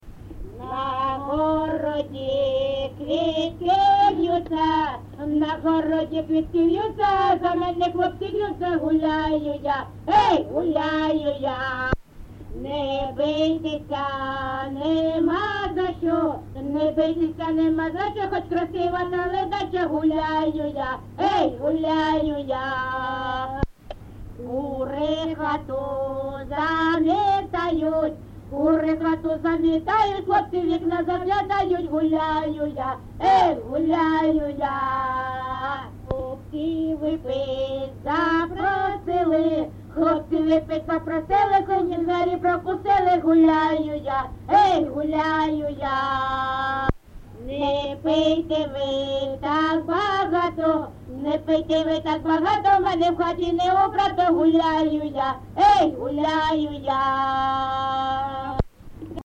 ЖанрПісні з особистого та родинного життя, Жартівливі
Місце записус. Гнилиця, Сумський район, Сумська обл., Україна, Слобожанщина